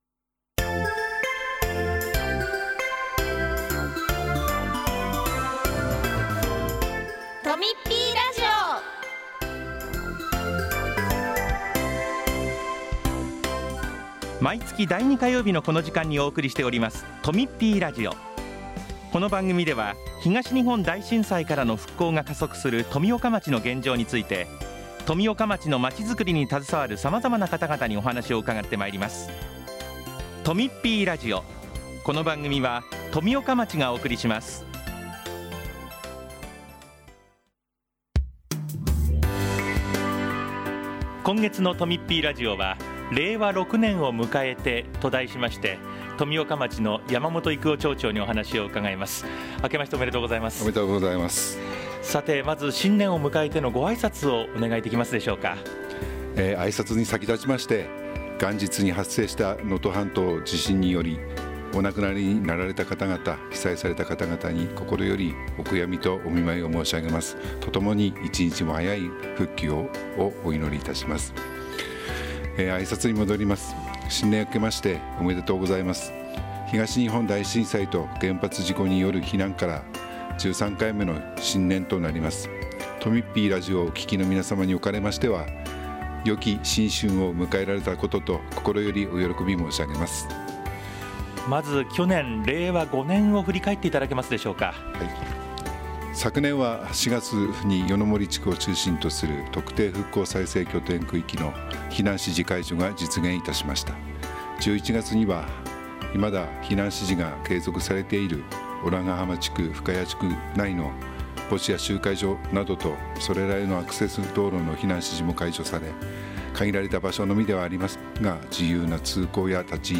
今回は、「令和6年を迎えて」というテーマで山本町長がお話ししています。